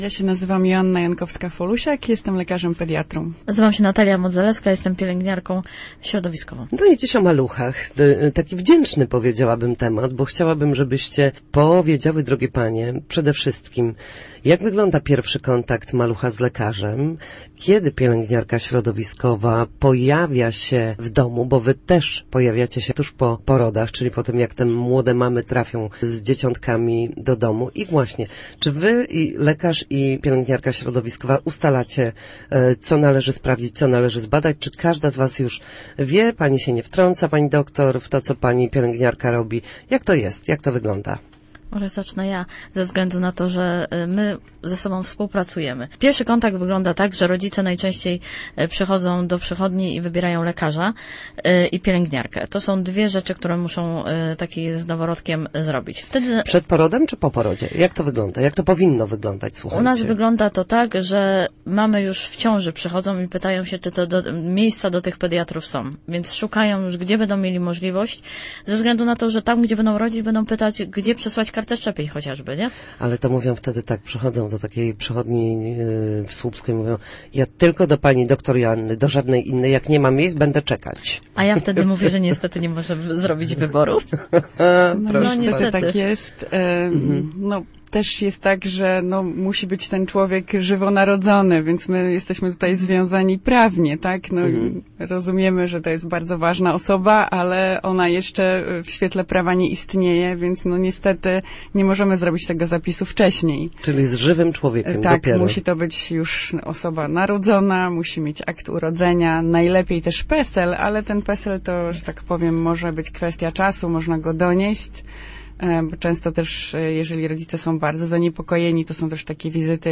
GOSC_PEDIATRA.mp3